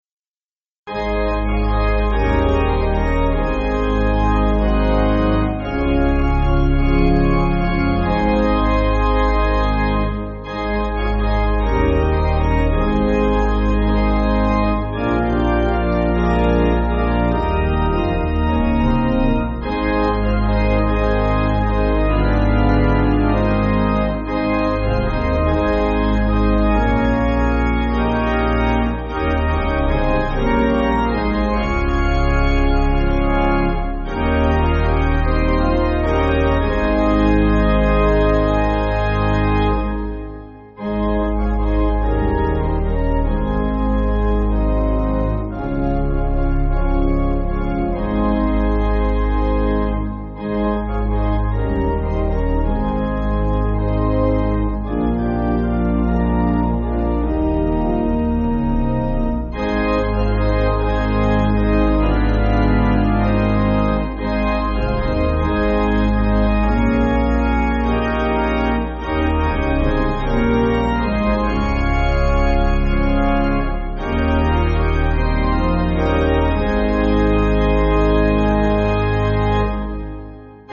Organ
(CM)   6/Ab